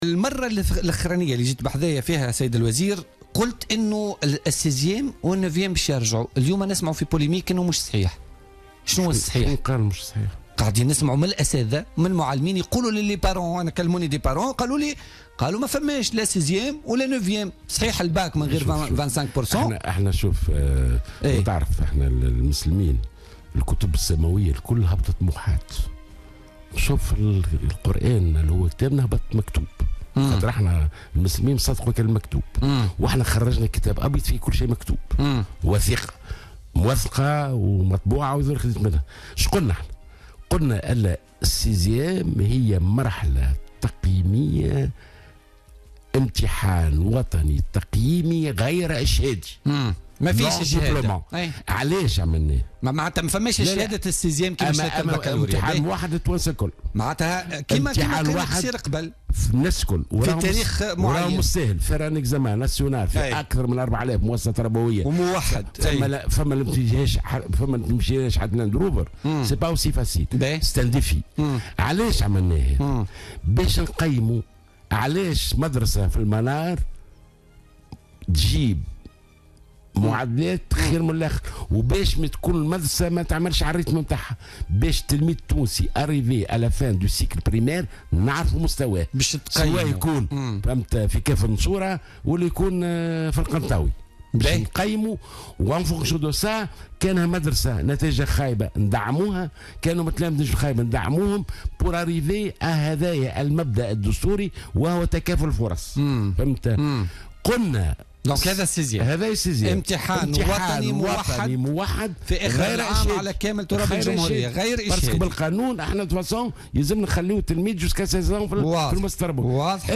أكد وزير التربية ناجي جلول ضيف بوليتيكا اليوم الجمعة 11 نوفمبر 2016 أن امتحان السيزيام سيكون امتحانا وطنيا موحدا يجرى في أكثر من 4 الاف مؤسسة تربوية في كامل تراب الجمهورية .